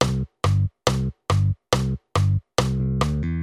Basová figúra z dominanty C dur do tóniky F dur (24 takt)
Basove-figury-polka-s-D-do-T_mp3.mp3